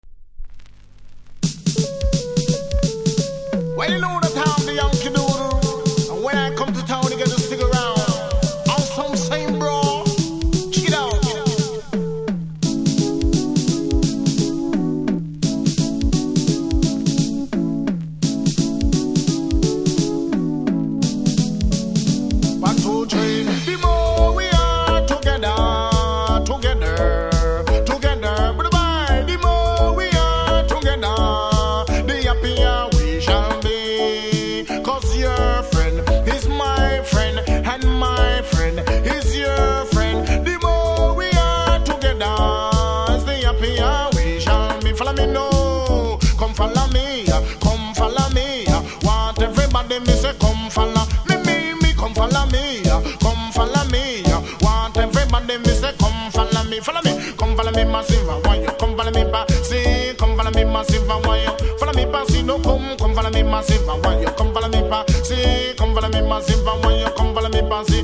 この盤は全てプレス起因で少しセンターずれありますがプレイは問題無いレベルなので試聴で確認下さい。